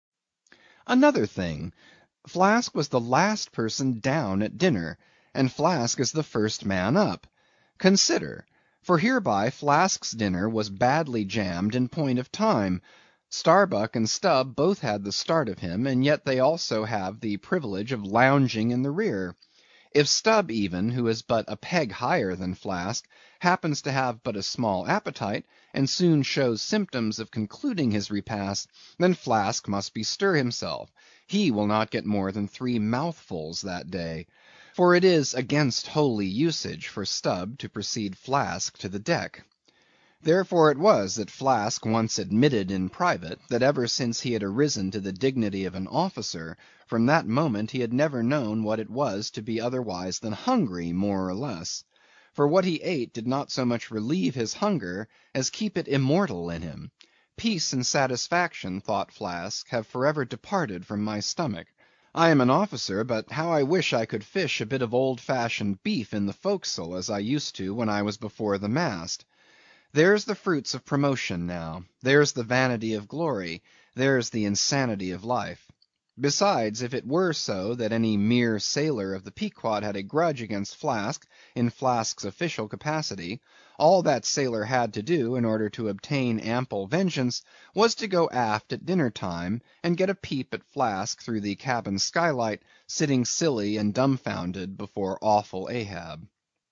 英语听书《白鲸记》第396期 听力文件下载—在线英语听力室